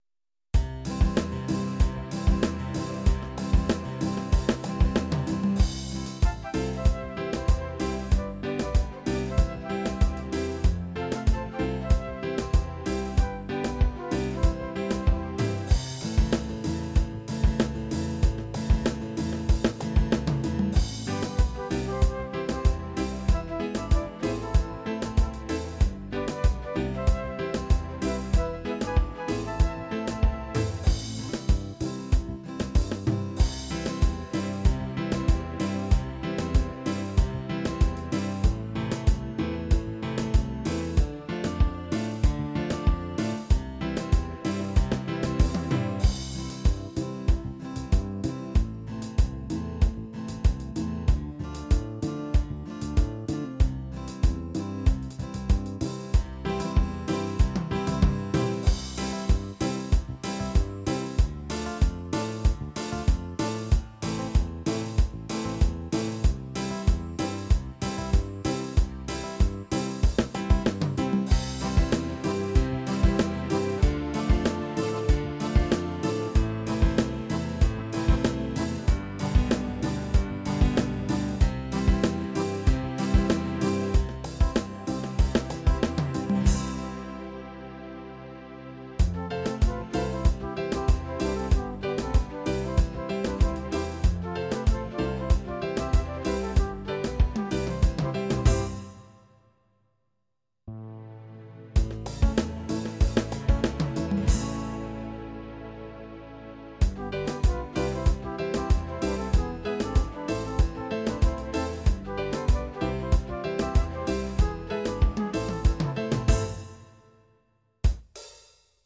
120 Styles / estilos / ritmos special brazil ( ritmos brasileiro )
120 styles / estilos / ritmos special brazil with 2 introducing 4 Main variations 4 fills break and 2 ending.